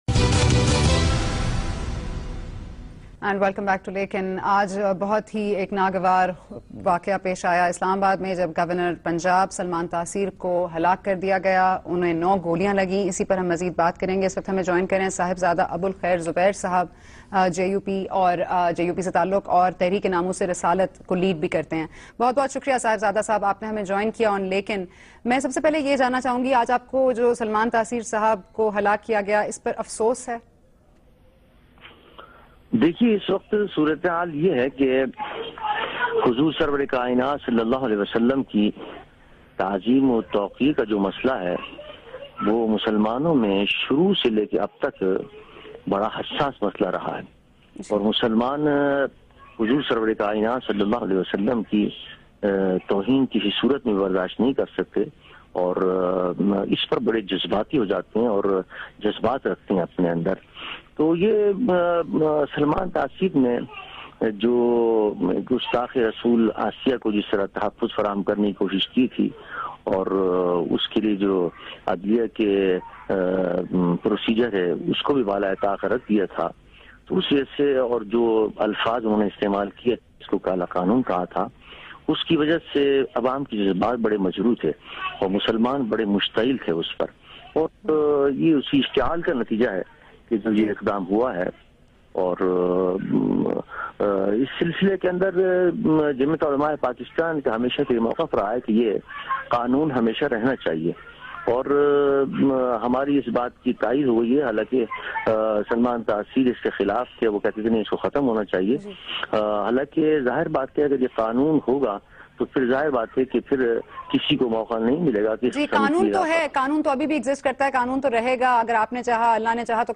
Javed Ahmad Ghamdi expresses his grief and comments on murder of Governor Punjab Salman Taseer in program Lakin on Geo New. Host: Sana Bucha